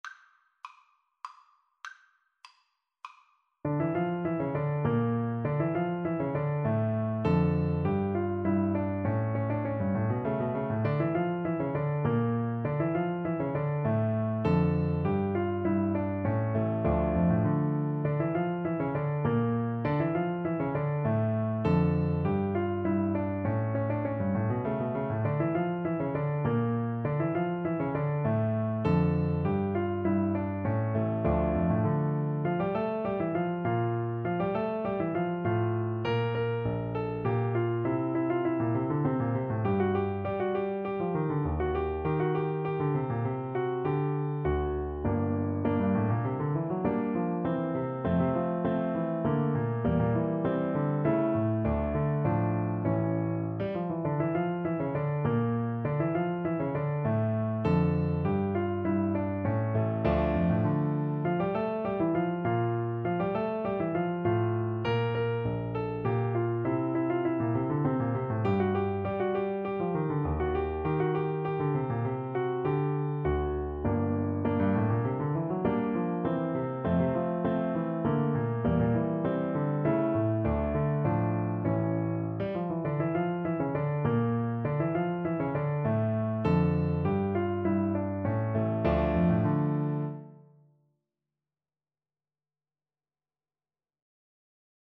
3/4 (View more 3/4 Music)
Allegretto = 100
Classical (View more Classical Trumpet Music)